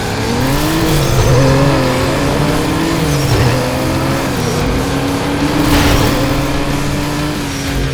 When using the nitro in the game (B button with the GC controller), you can hear the audio crackle.
The crackling in the dump is less bad than when actually playing.